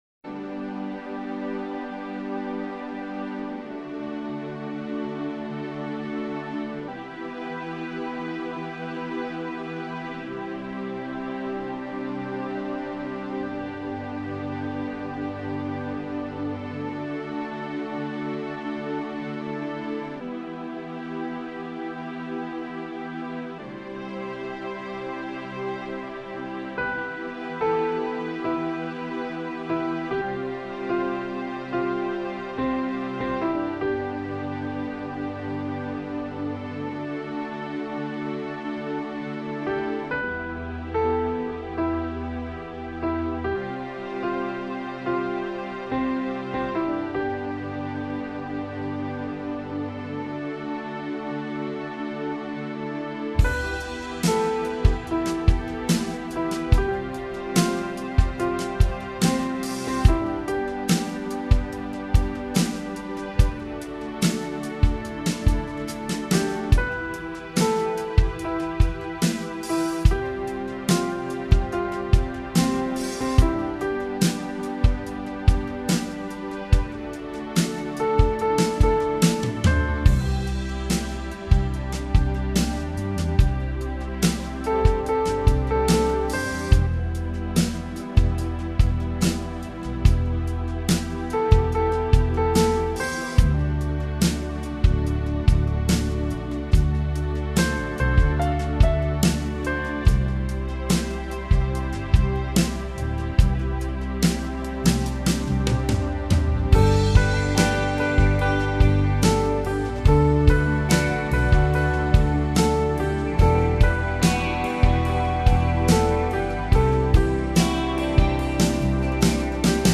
My backing is in G.